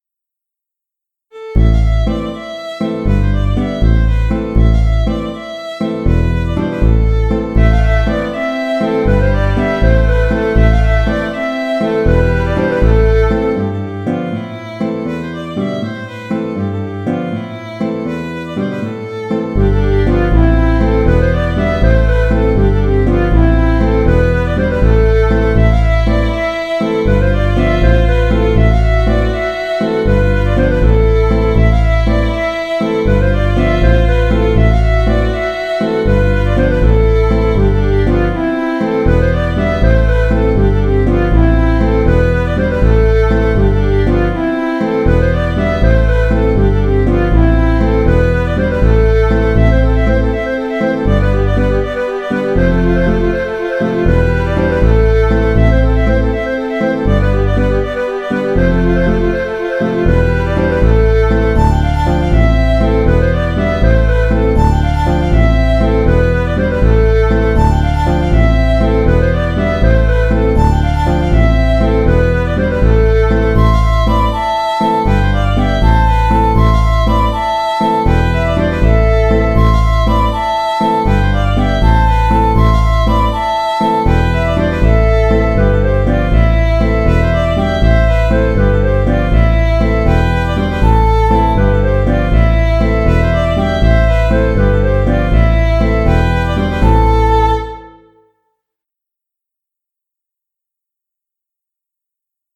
Limousine (La) v2 (Bourrée ) - Musique folk
La Limousine v2 Bourrée 3 temps C’est une autre version de la bourrée du même nom qui figure juste précédemment. Le thème est identique, avec quelques variations. Les contrechants diversifient ceux de la version 1.
Musique folk